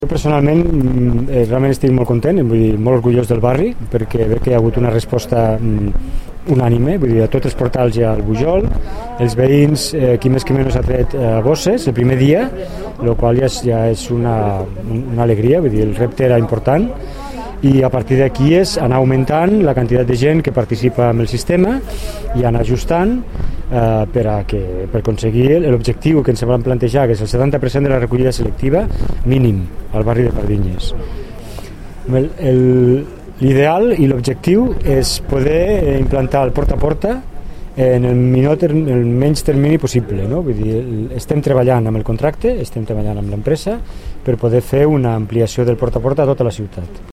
tall-de-veu-sergi-talamonte